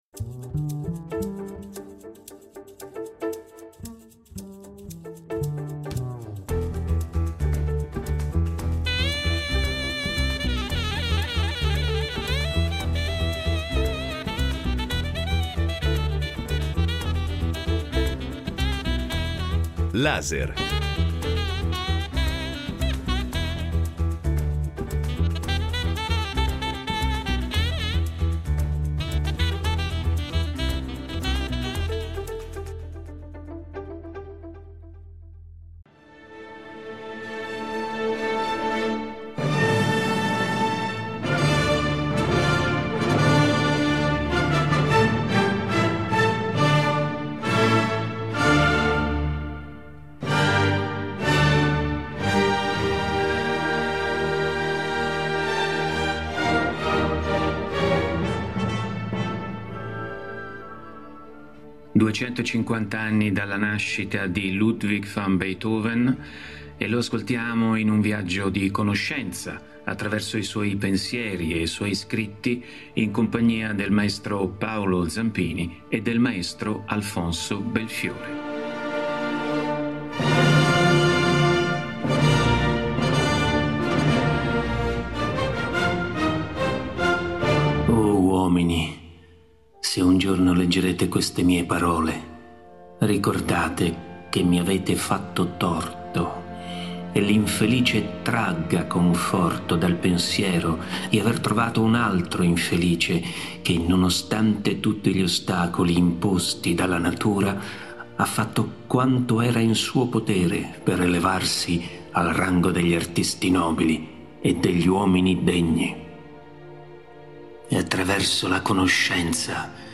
A duecentocinquanta anni dalla nascita ripercorriamo un viaggio a ritroso nel tempo in compagnia del genio sublime di Beethoven. Ho cercato dando voce al personaggio Ludwig di mescolare in alcune lettere scritti e pensieri le caratteristiche e i risvolti psicologici della sua vita sottolineando il fatto che durante tutta la sua esistenza vi fu un contrasto tra il suo evidente successo, anche se a volte venato da difficoltà finanziarie, e uno stato di insoddisfazione e sovente di disperazione.